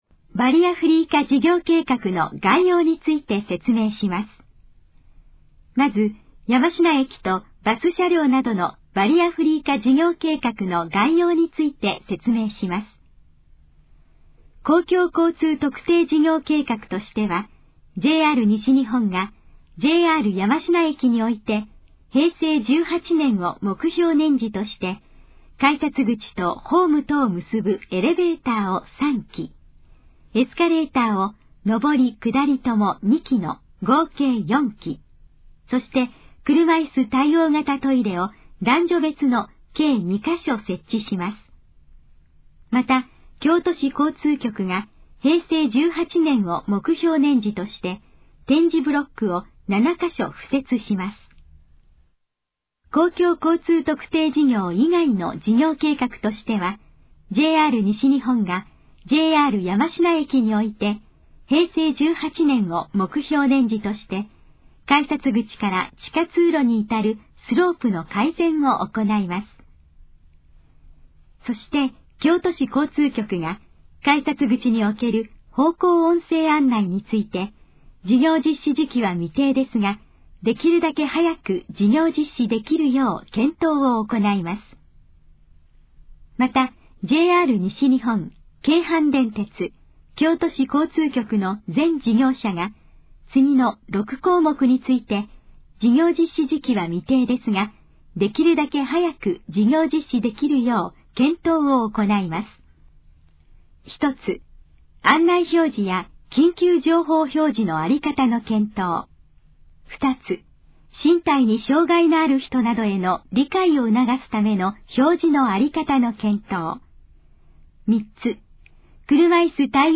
以下の項目の要約を音声で読み上げます。
ナレーション再生 約351KB